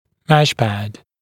[‘meʃpæd][‘мэшпэд]сетчатый профиль основания брекета, «сетка в основании»